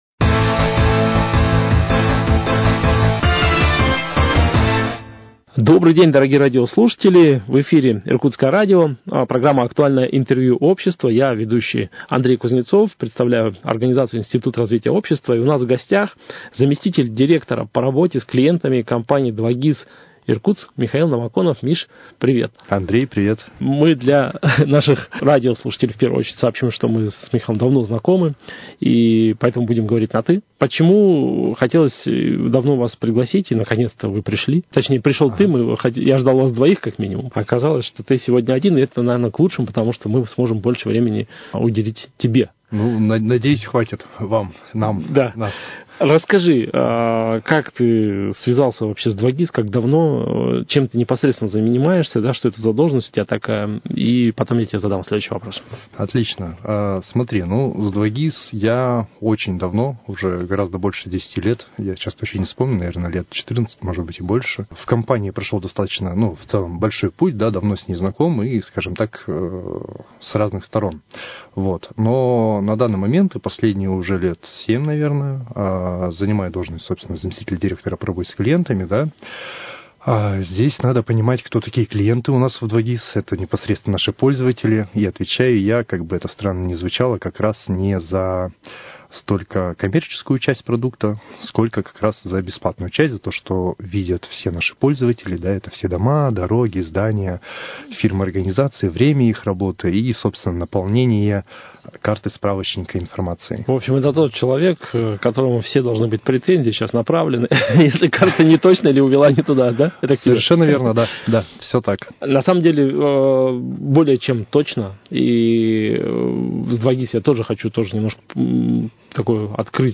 Актуальное интервью